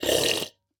Minecraft Version Minecraft Version snapshot Latest Release | Latest Snapshot snapshot / assets / minecraft / sounds / mob / wandering_trader / drink_milk3.ogg Compare With Compare With Latest Release | Latest Snapshot
drink_milk3.ogg